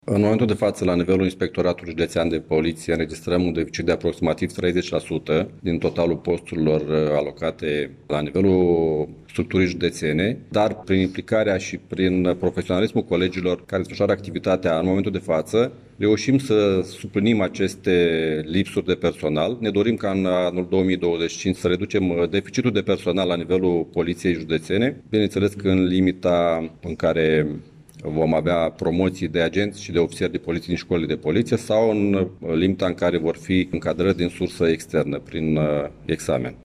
Comandantul IPJ Iași, chestorul Costel Gâtlan, a detaliat, la ședința de bilanț a activității pe anul trecut, că în domeniul furturilor, au fost înregistrate aproximativ 4.300 de cazuri, cu aproape 300 mai puține față de acum un an.